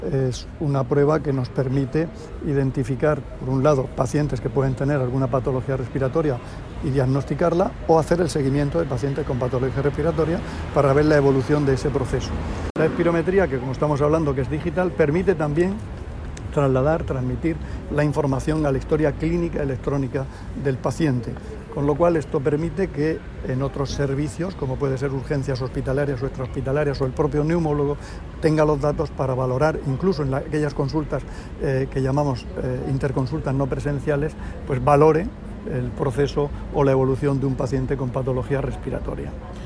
Declaraciones del consejero de Salud sobre la instalación de espirómetros digitales de los centros de salud de la Región.